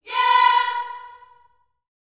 YEAH B 4A.wav